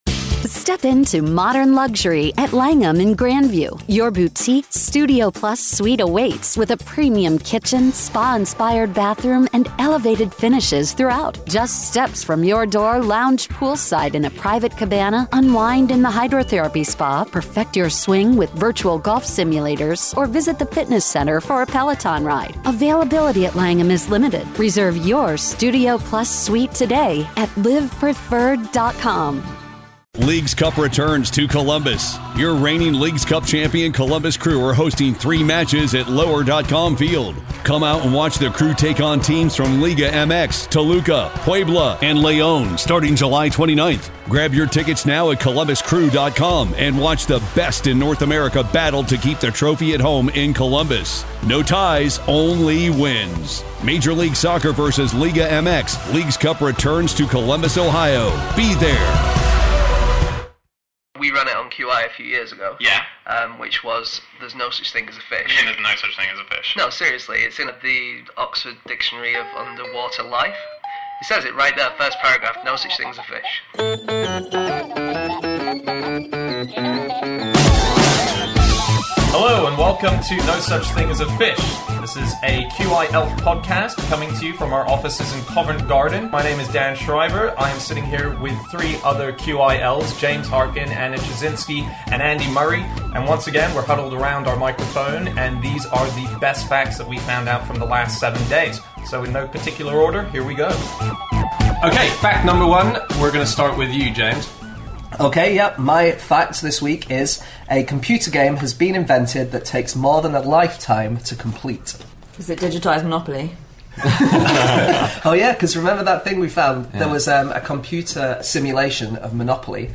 This week in the QI Office